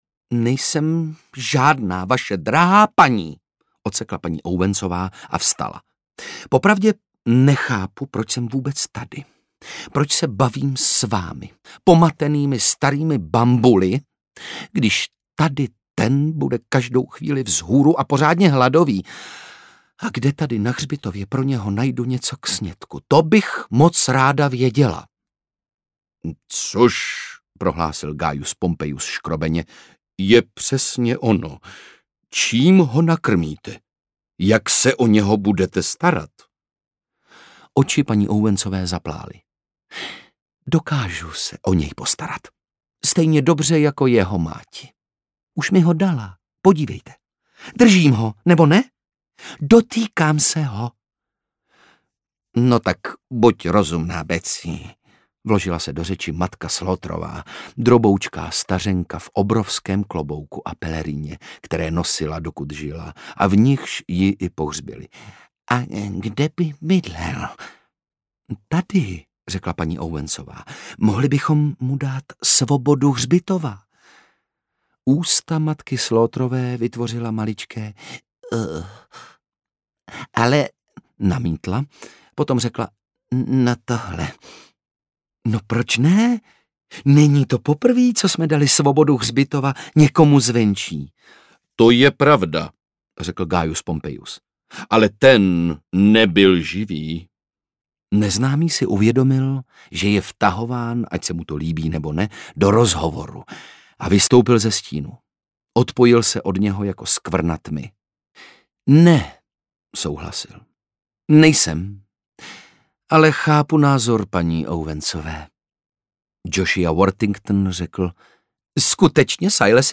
Kniha hřbitova audiokniha
Ukázka z knihy
• InterpretOndřej Brousek